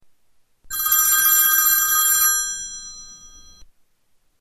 iphone old phone